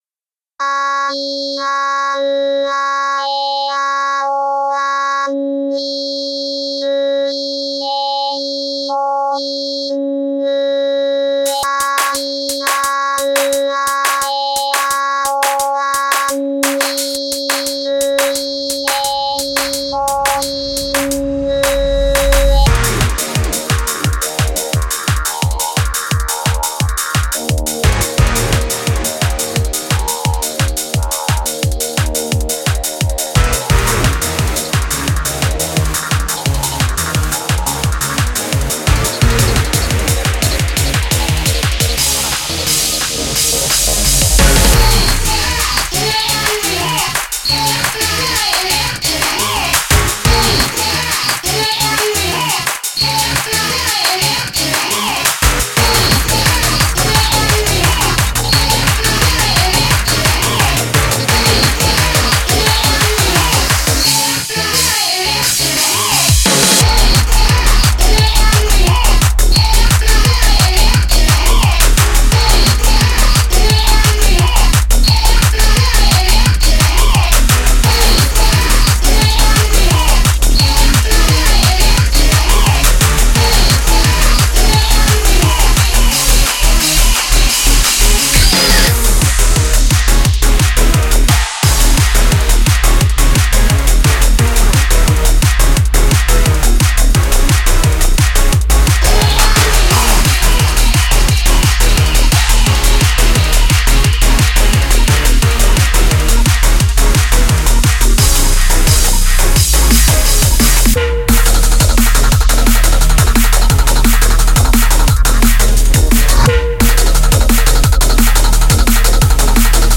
BPM174-174
Audio QualityPerfect (High Quality)
Full Length Song (not arcade length cut)